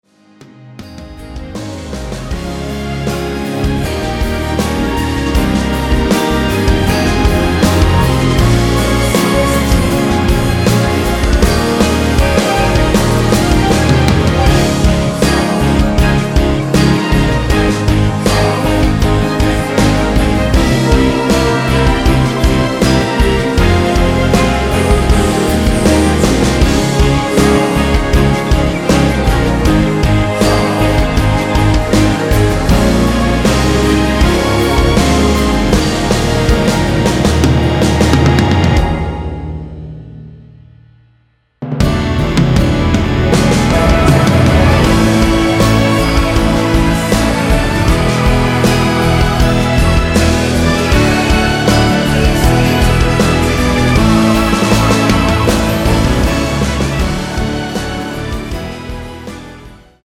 원키에서(-3)내린 코러스 포함된 MR입니다.
Ab
앞부분30초, 뒷부분30초씩 편집해서 올려 드리고 있습니다.